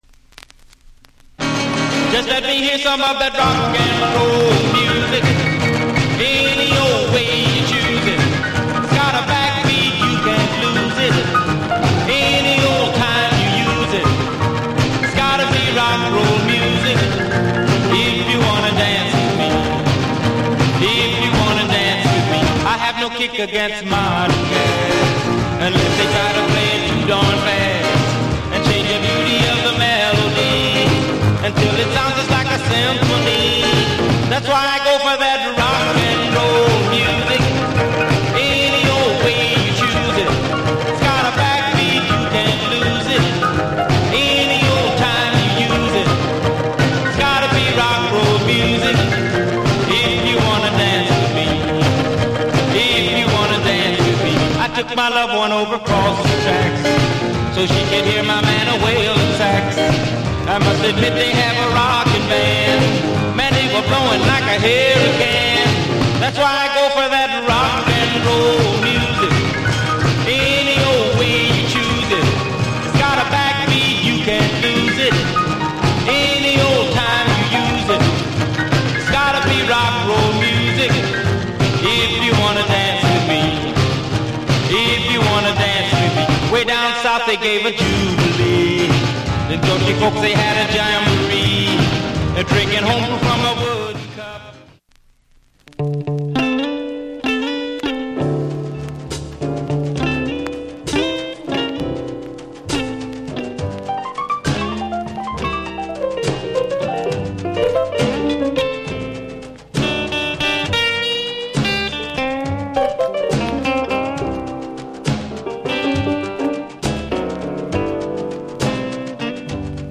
(R&B)
オリジナル盤7インチ